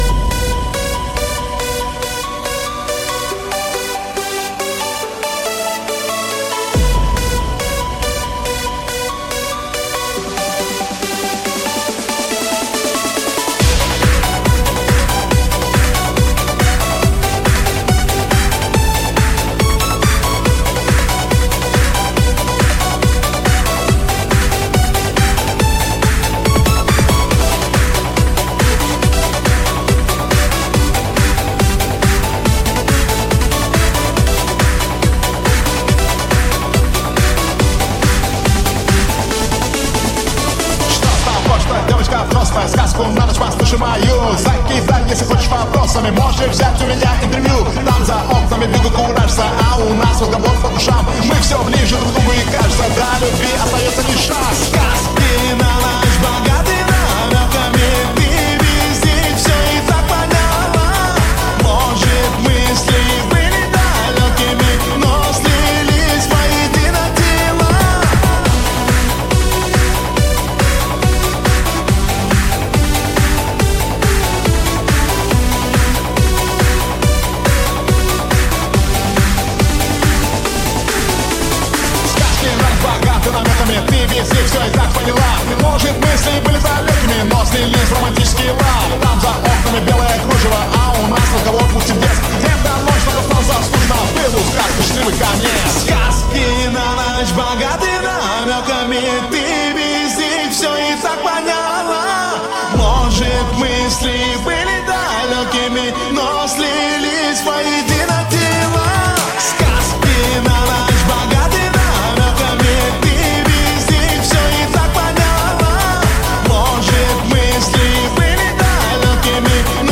_ Euro Dance Mix _